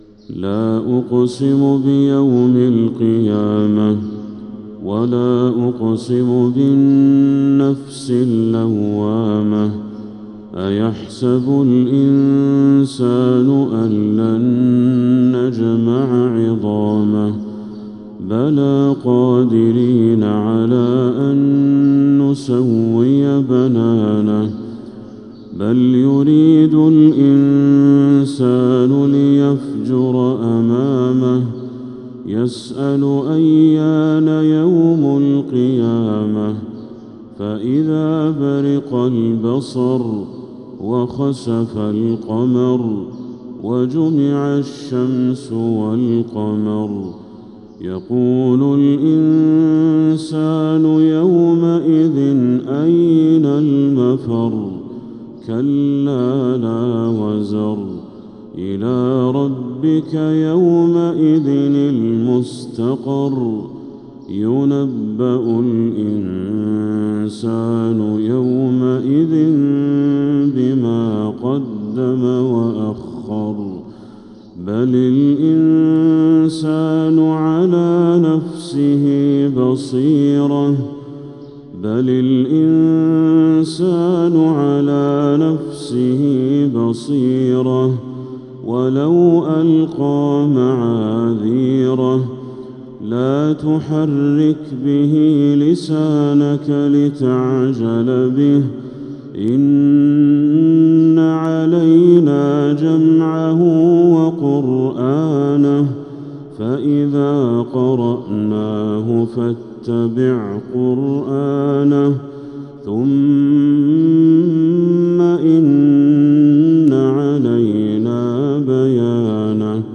تلاوات الحرمين